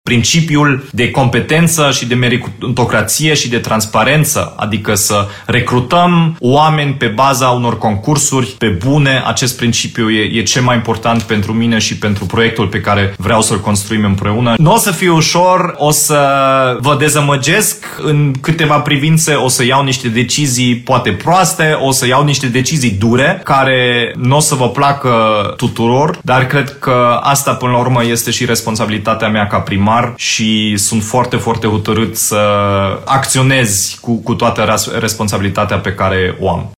Totdată, într-o transmisie live pe Facebook, Dominic Fritz a precizat că are în vedere reformarea aparatului Primărei, pentru ca funcționarii să fie cu adevărat eficienți.
Dominic Fritz a precizat, în context, că este adeptul măsurilor dure și că nu va ezita să le aplice, chiar dacă acest lucru nu va fi pe placul unor persoane: